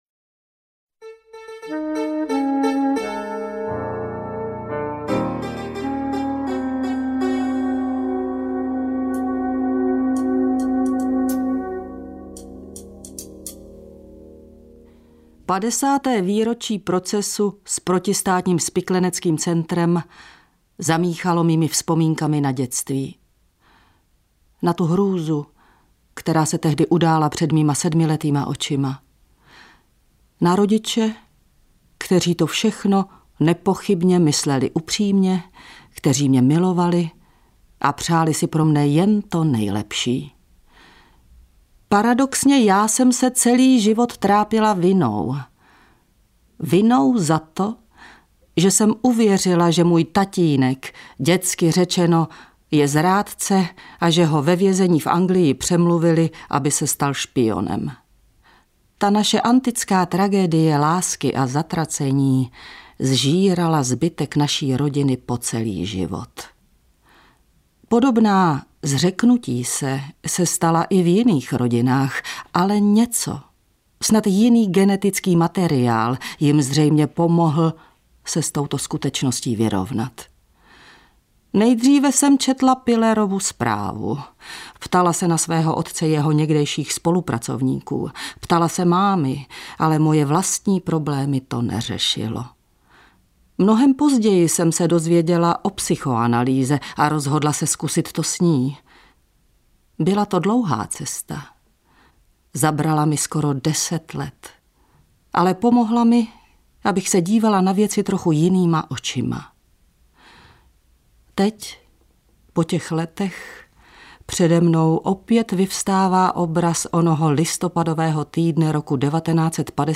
Interpret:  Hana Frejková
AudioKniha ke stažení, 10 x mp3, délka 4 hod. 25 min., velikost 605,6 MB, česky